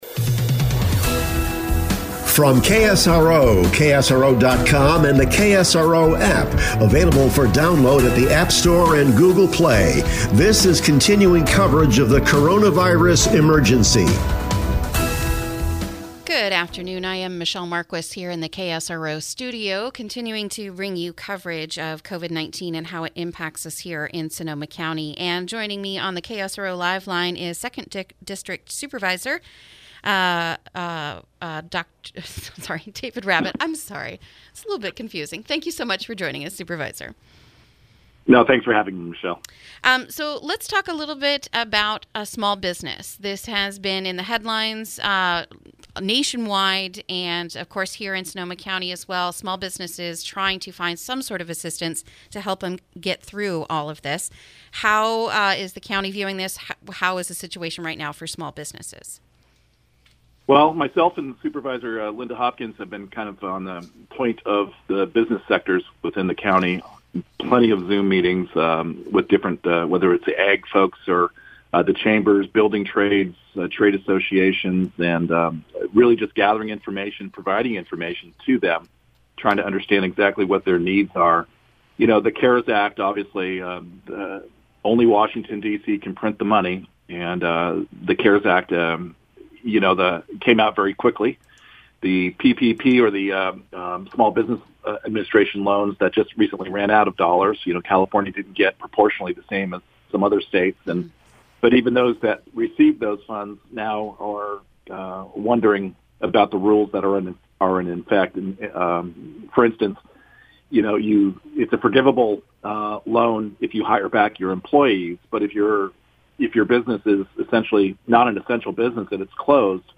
Interview: Supervisor David Rabbitt on the economic impacts of coronavirus on Sonoma County